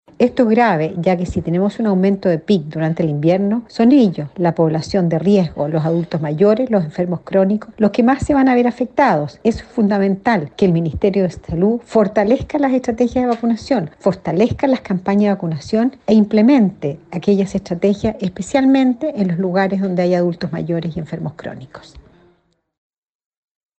“Esto es grave, ya que si tenemos un aumento de peak durante el invierno, son ellos, la población de riesgo, los adultos mayores y los enfermos crónicos, los que más se van a ver afectados”, afirmó la exautoridad, en conversación con Radio Bío Bío.